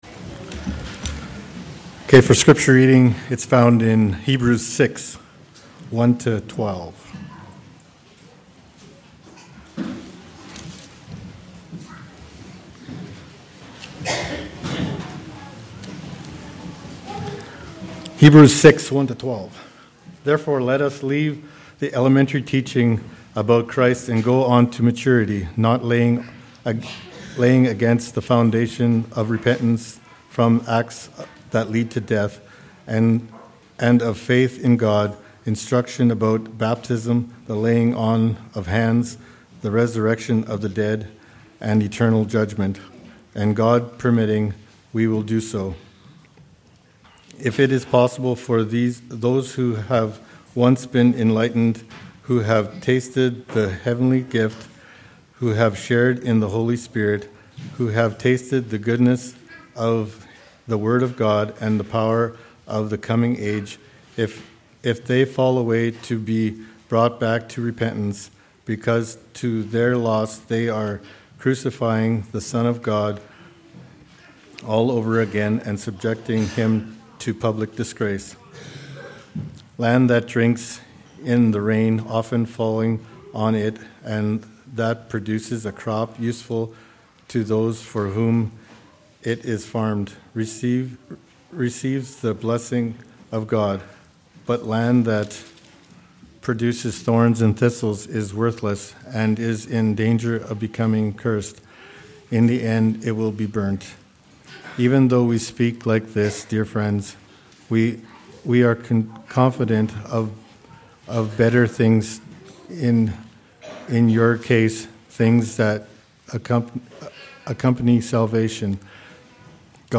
Nov. 10, 2019 – “An Anchor for the Soul” – Hebrews 6:4-12 – Hope in a Hard Place Nov. 10, 2019 – “An Anchor for the Soul” – Hebrews 6:4-12 – Hope in a Hard Place Download Posted in Sermons .